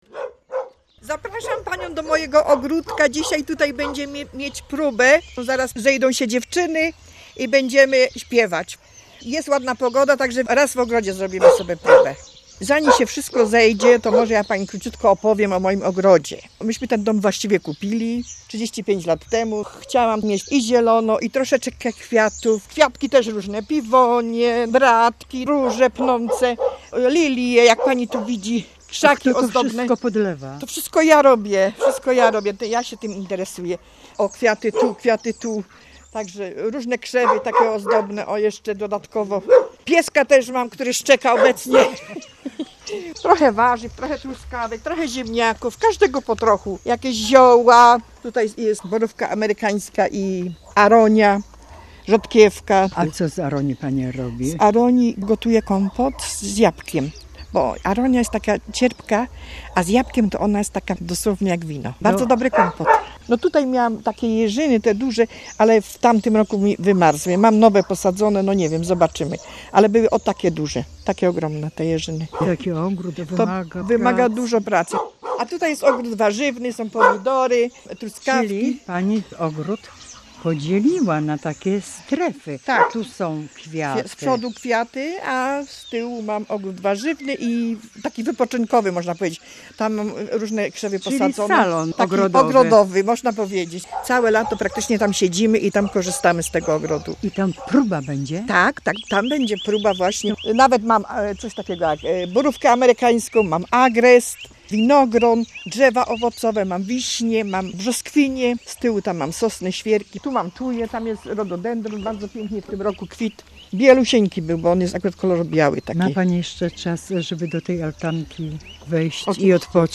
Zapraszamy wysłuchania reportażu "Ogrody", który został wyemitowany w dniu 15 lipca 2013 roku o godzinie 20.00 w Radiu Opole w ramach audycji pt.